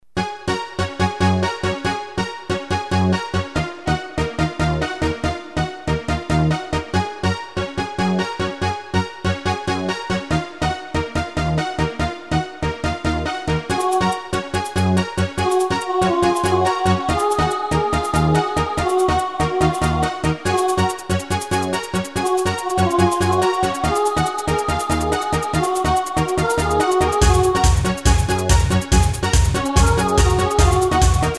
Tempo: 142 BPM.
MP3 with melody DEMO 30s (0.5 MB)zdarma